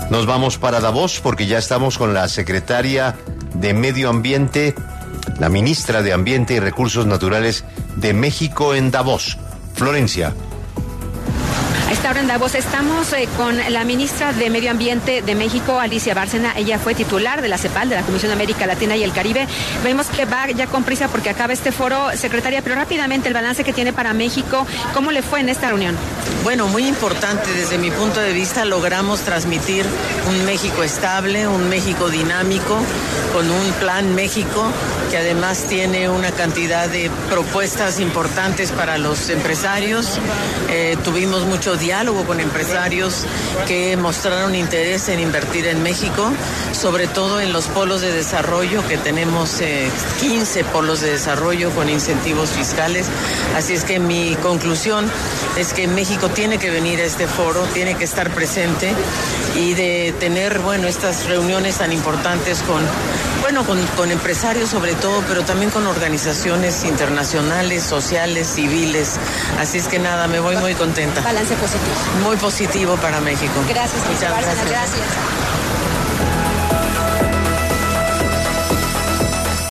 Alicia Bárcena, diplomática, bióloga y académica mexicana, quien es secretaria de Medio Ambiente y Recursos Naturales de México, habló en los micrófonos de 6AM W, con Julio Sánchez Cristo, de Caracol Radio, para hablar de el papel de su país en el Foro Económico Mundial que se lleva a cabo en Davos, Suiza.